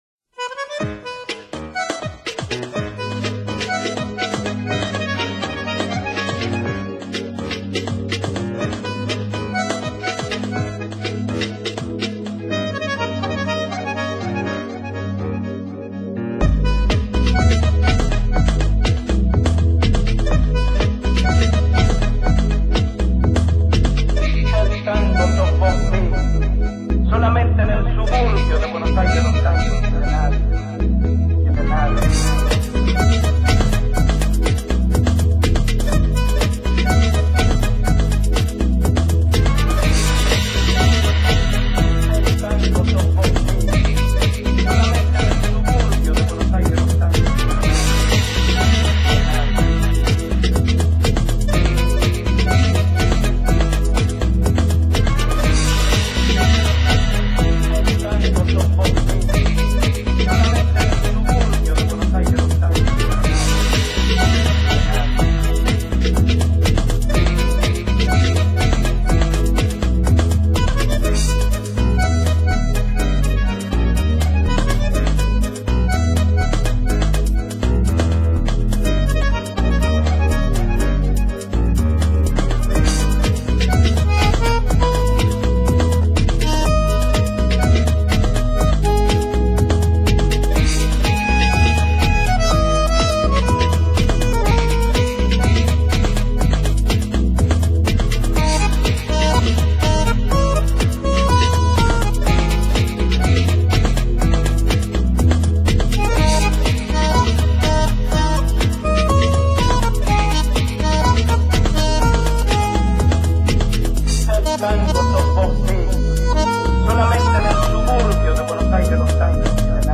version Electro